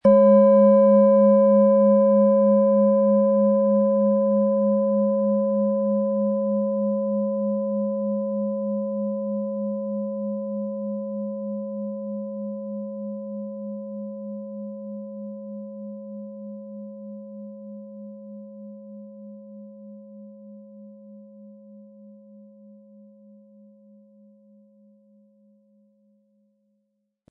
OM Ton
• Mittlerer Ton: Mond
Den passenden Klöppel erhalten Sie umsonst mitgeliefert, er lässt die Schale voll und wohltuend klingen.
MaterialBronze